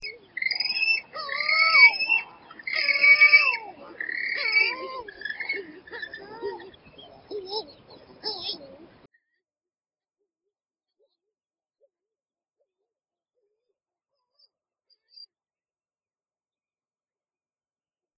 PARDELA OSCURA
pardelaoscura.wav